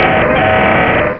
Cri de Galeking dans Pokémon Rubis et Saphir.